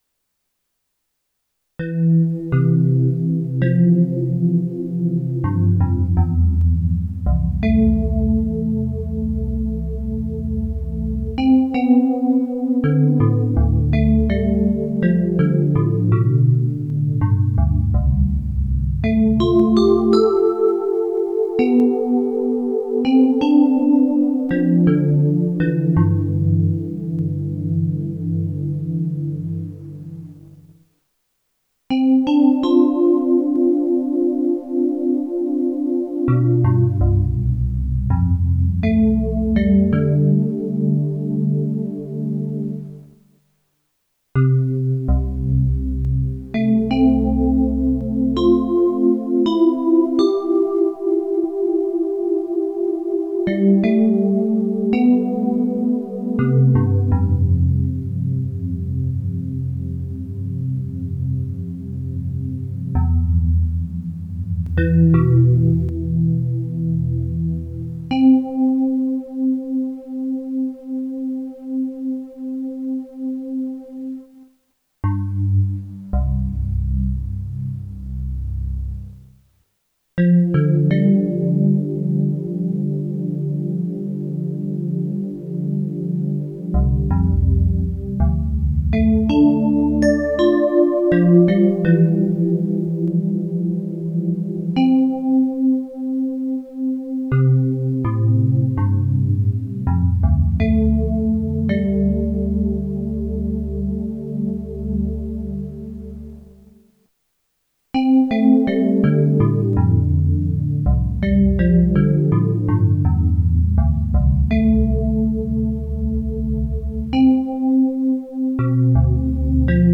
Romarin enregistrée dans le jardin du bas à Saint Maximin le 7 avril 2024 à 19h13
romarin_en_fleur.mp3